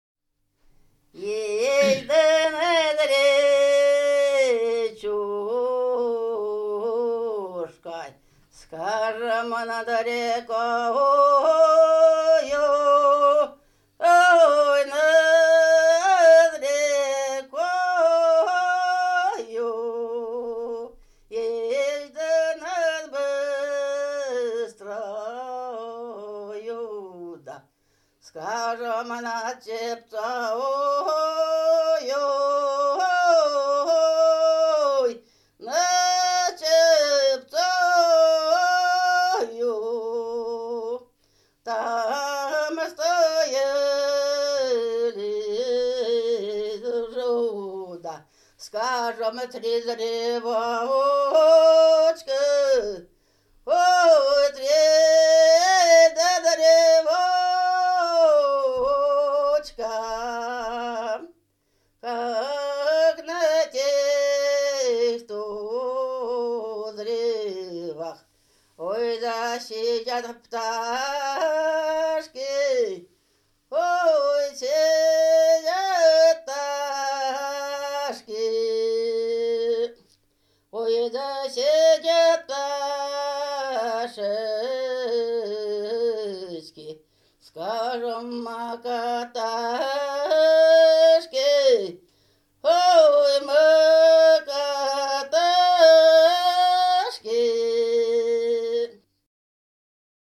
Молодецкие песни чаще исполнялись «во пирушке за столом», «на гости́тбах».
Экспедиционные записи фиксируют, что особая роль в ансамбле отводилась мужчинам, они нередко запевали песни.
Особенности мужской лирической традиции проявляются в преобладании закономерностей повествовательного типа интонирования, что способствует выразительному «произнесению» поэтического слова.
Лирическая песня «Есть-то над ричушкой»